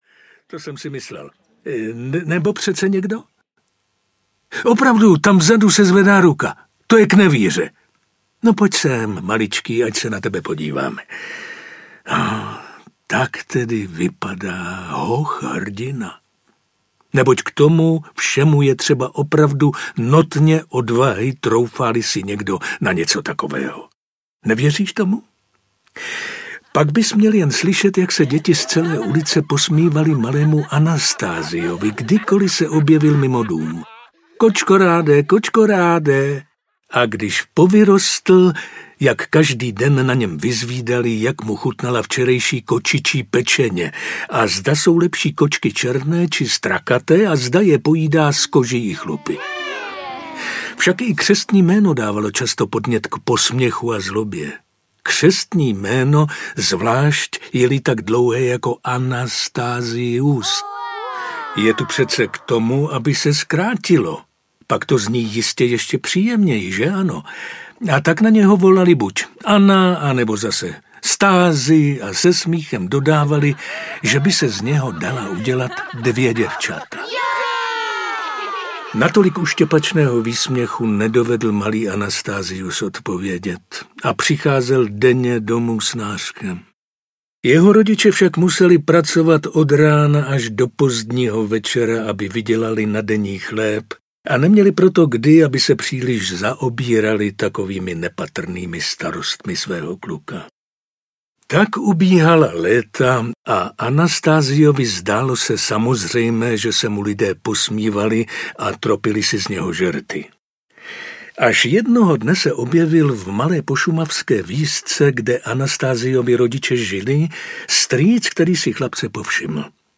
Anastázius Kočkorád, velký kouzelník audiokniha
Ukázka z knihy